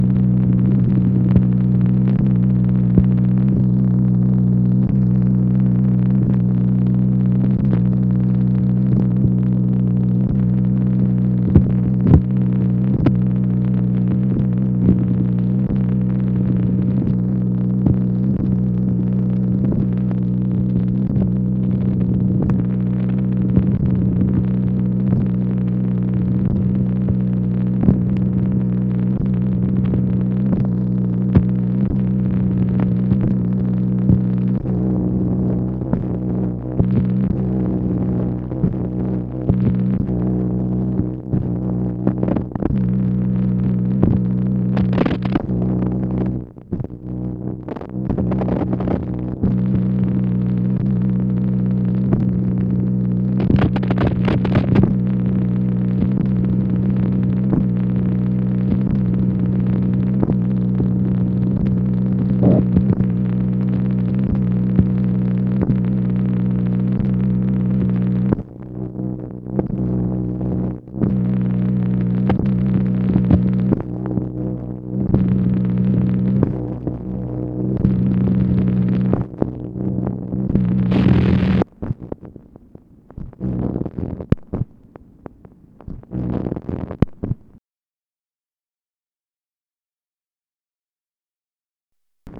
MACHINE NOISE, February 17, 1964
Secret White House Tapes | Lyndon B. Johnson Presidency